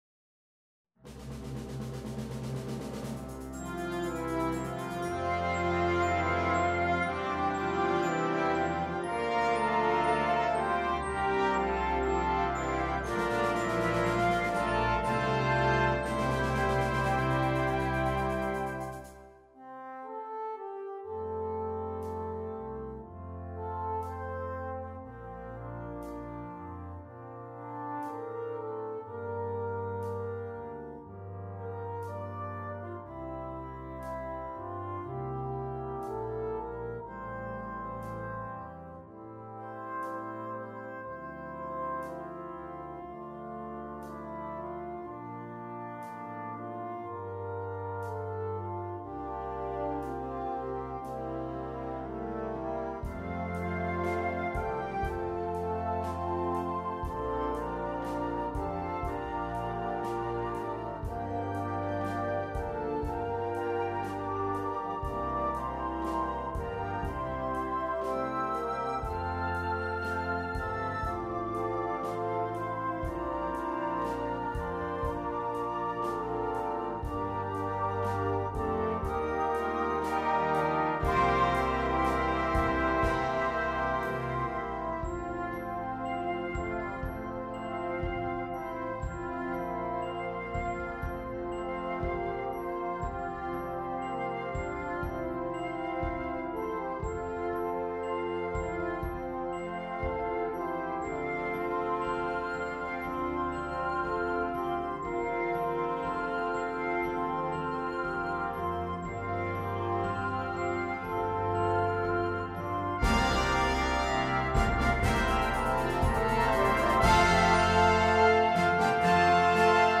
Gattung: Flügelhorn-Bariton-Duett mit Blasorchester
Besetzung: Blasorchester